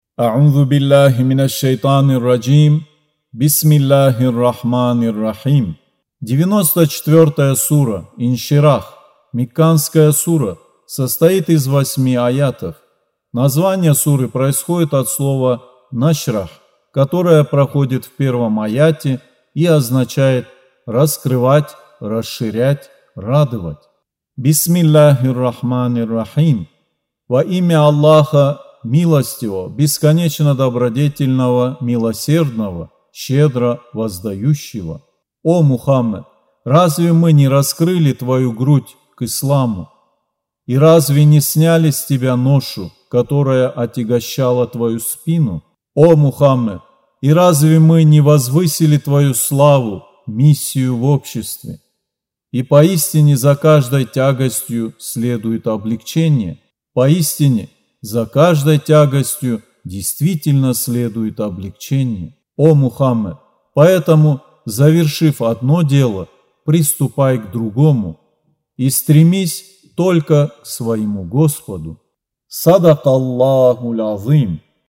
Аудио Коран 94.